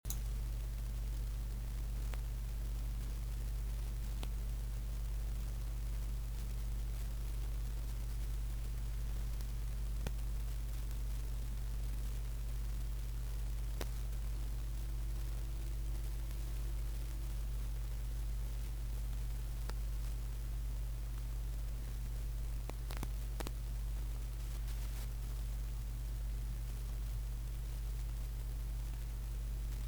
Странный шум и треск у XLR микрофона
Приобрел аудиокарту Behringer UMC22 и микрофон Behringer C-1, по началу я столкнулся с электрическим жужжащим шумом, который сумел исправить и разобраться в причине данной проблемы, после некоторого времени снова появился этот шум, разобрав XLR решил его пошевелить и посмотреть как можно исправить этот шум, исправив его появилась ГЛАВНАЯ проблема которую не могу решить уже 6 часов, появился странный шум, который время от времени пропадает, но не исчезает полностью, я уже не знаю в чем может быть причина, может дело в кабеле, а может в аудиокарте.
Ниже прикрепил запись этих шумов Вложения шум.mp3 шум.mp3 1,1 MB · Просмотры: 4.867